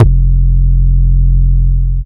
Index of /Antidote Advent/Drums - 808 Kicks
808 Kicks 08 F.wav